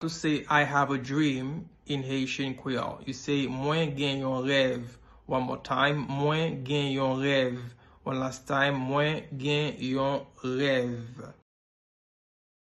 Listen to and watch “Mwen gen yon rèv” audio pronunciation in Haitian Creole by a native Haitian  in the video below:
34.I-have-a-dream-in-Haitian-Creole-–-Mwen-gen-yon-rev-pronunciation-.mp3